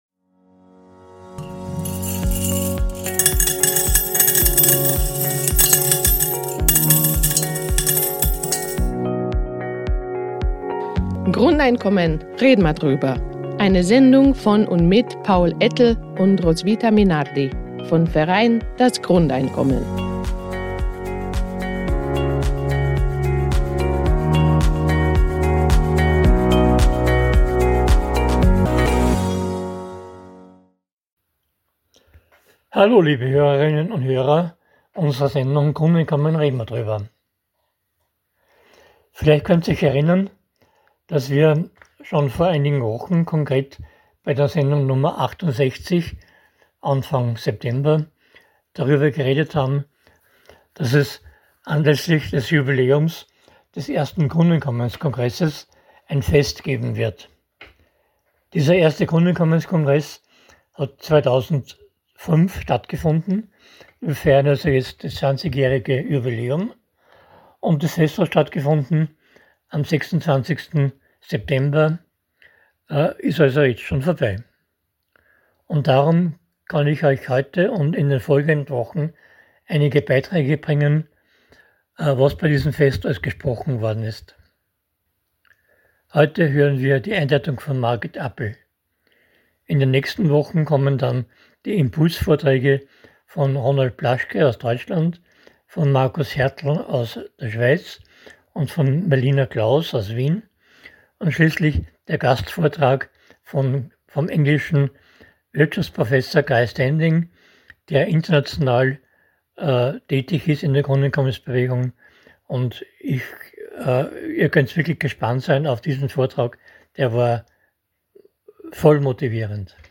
Dieser Beitrag ist der erste von 5 Beiträgen über das Fest anlässlich des 20-Jahre-Jubiläums des ersten Grundeinkommenskongresses im deutschsprachigen Raum.